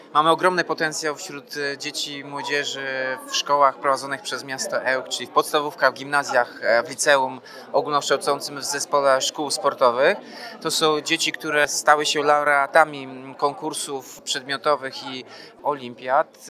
Z tak dobrych wyników uczniów dumny jest Tomasz Andrukiewicz, prezydent Ełku.